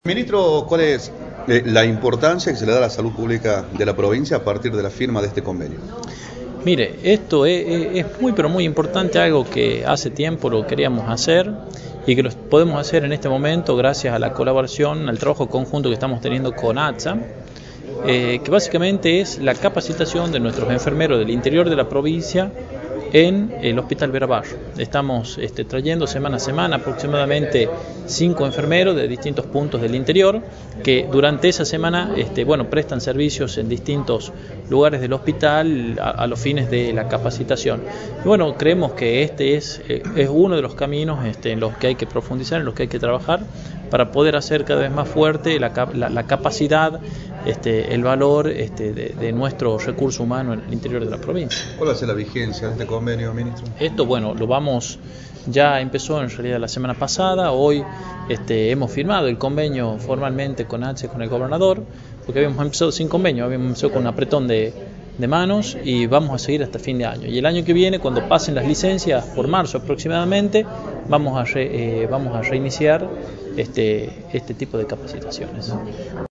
Luis Beder Herrera, gobernador